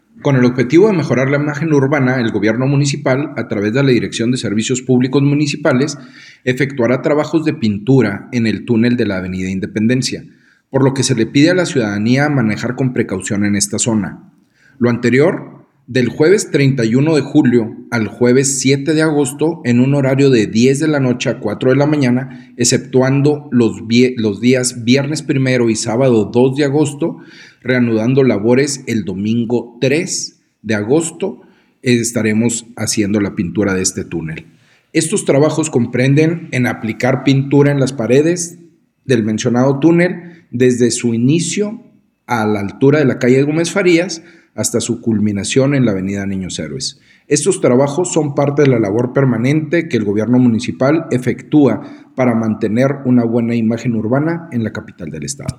AUDIO-Jose-Luis-de-Lamadrid-director-de-Servicios-Publicos-Muncipales-Trabajos-en-tunel-de-la-Independencia.mp3